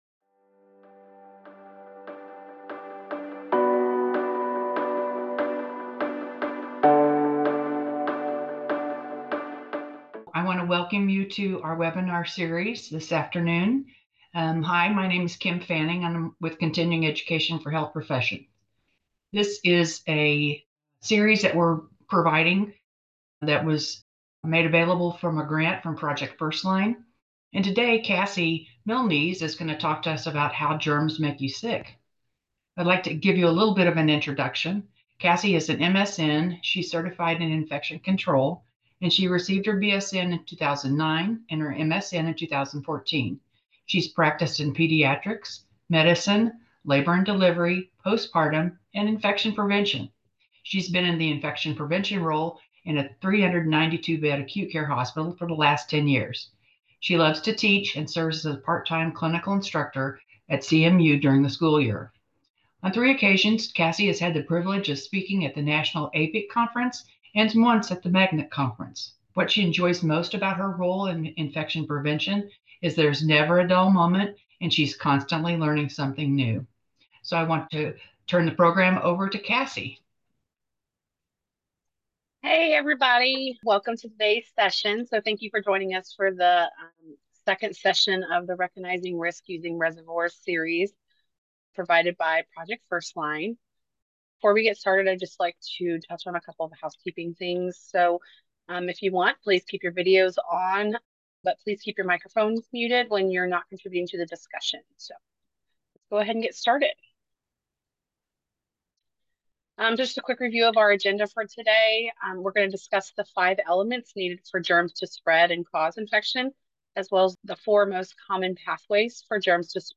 The audio recordings are based on webinars that were presented in 2024. Presenters include current infection prevent experts who share evidence-based CDC resources. Topics include, Respiratory Virus Spread, Environmental Cleaning and Disinfection and Basic Personal Protective Equipment (PPE) in Healthcare.